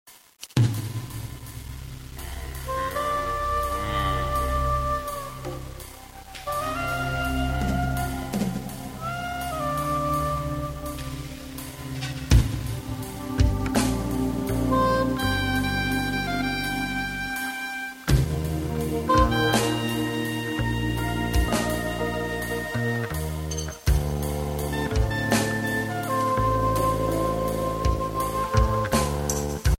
sax, vocal, keyb, electric-drums